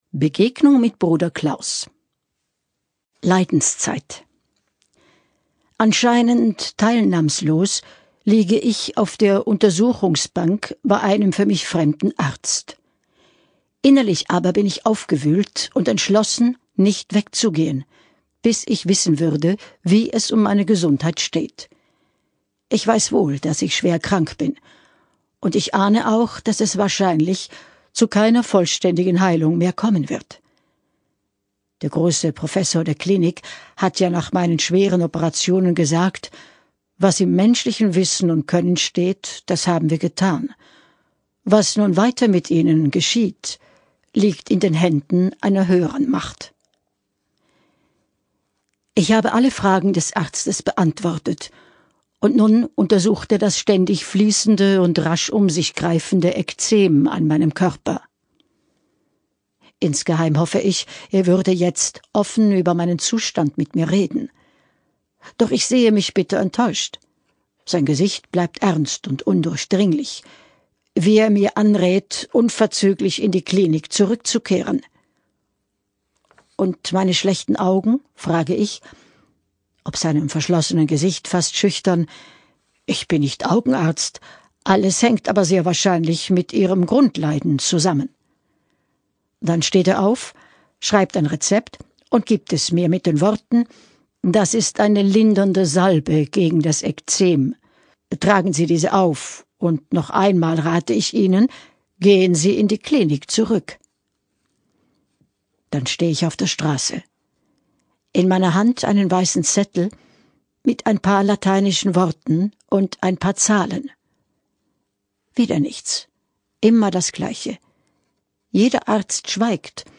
Die Erzählerin